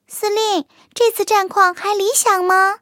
卡尔臼炮查看战绩语音.OGG